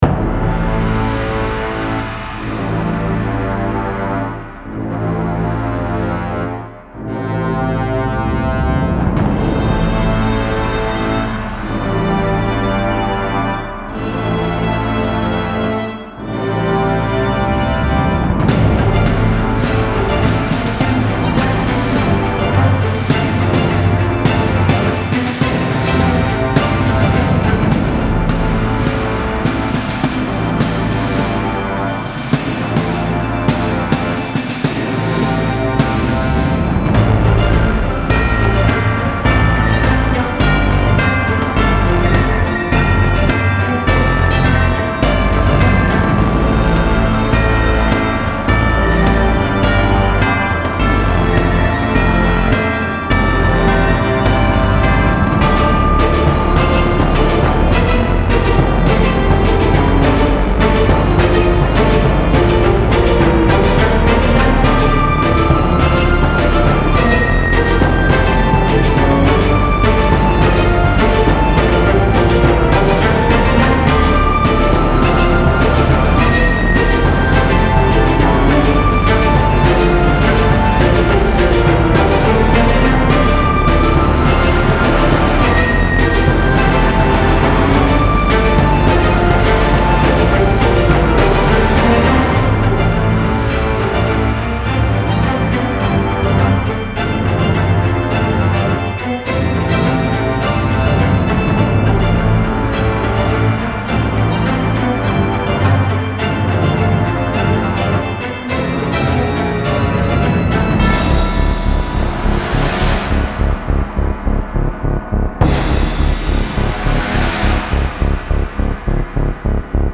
Style Style OrchestralSoundtrack
Mood Mood AggressiveDarkEpicIntense
BPM BPM 104